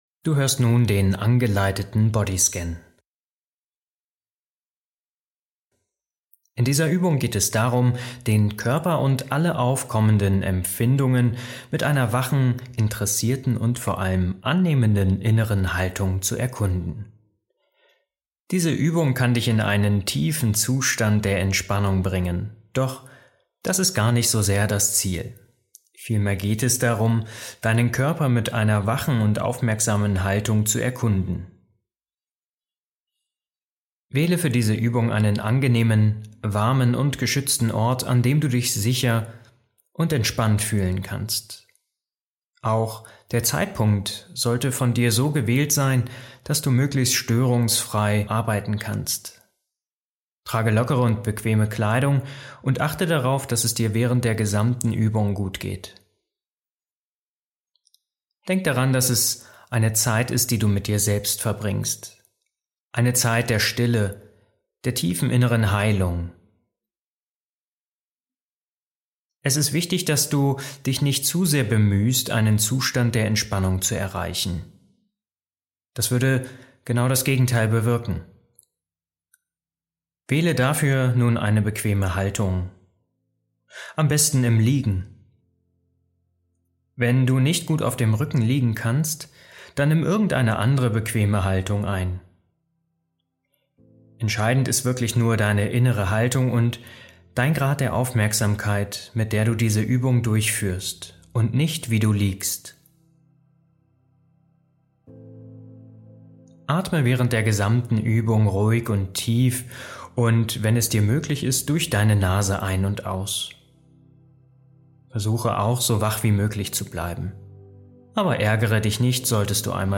Beschreibung vor 2 Jahren In dieser Episode erwartet dich der beliebte 35-minütige Bodyscan nach Jon Kabat-Zinn – jetzt auch mit sanfter Meditationsmusik hinterlegt. Diese Übung, die zentraler Bestandteil des MBSR-Programms (Mindfulness-Based Stress Reduction) ist, führt dich durch eine achtsame Wahrnehmung deines Körpers. Der Bodyscan hilft dir, Spannungen zu lösen, innere Ruhe zu finden und deinen Geist zu klären.